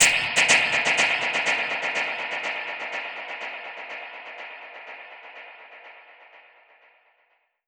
Index of /musicradar/dub-percussion-samples/125bpm
DPFX_PercHit_C_125-05.wav